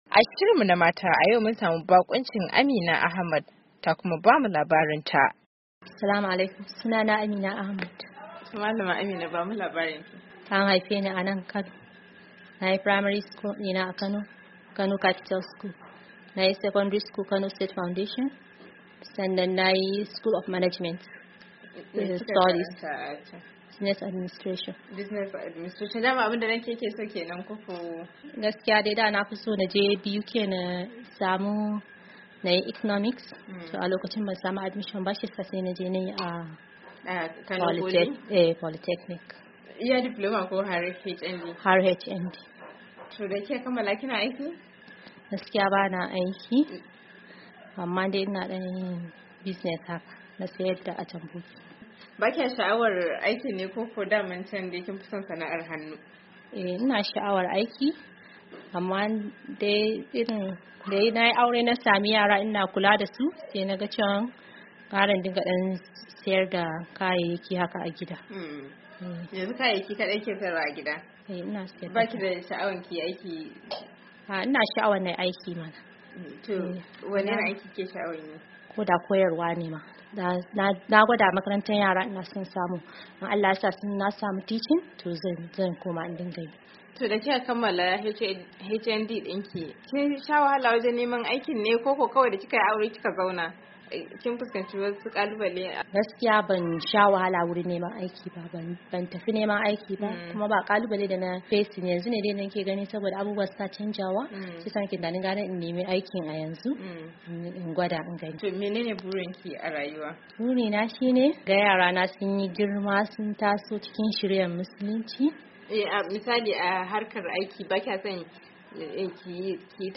wata uwa matar aure kuma ‘tar kasuwa ce ta furta haka a hirarsu da wakiliyar Dandalinvoa